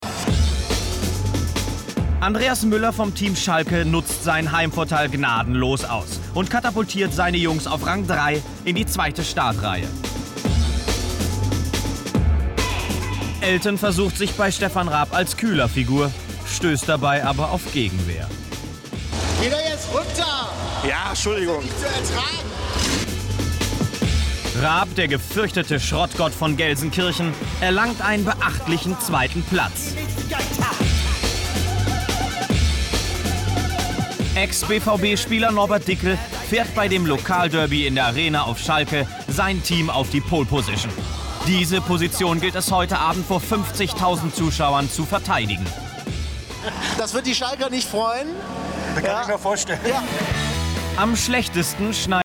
• Sprechproben